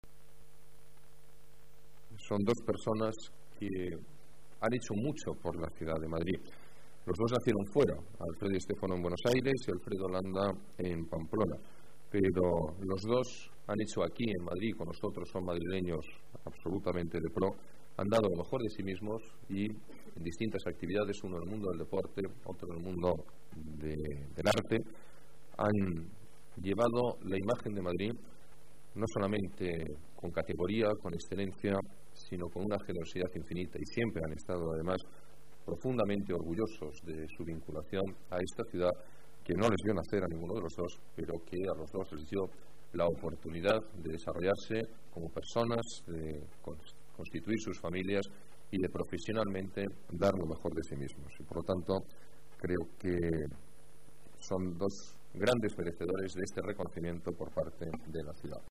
Nueva ventana:Declaraciones del alcalde de Madrid, Alberto Ruiz-Gallardón: medalla de Oro a Di Stéfano